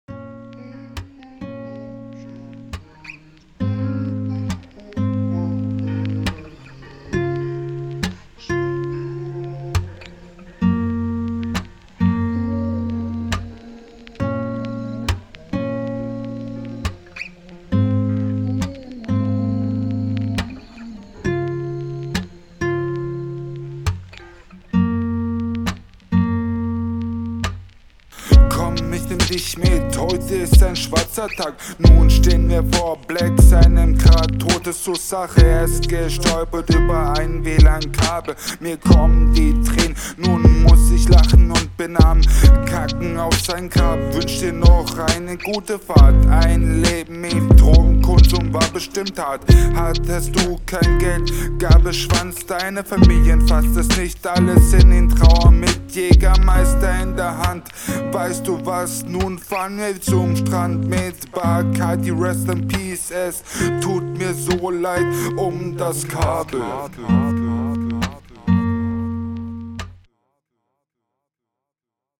Die Stimme harmoniert 100x besser mit dem Beat als in deiner RR und die Lines …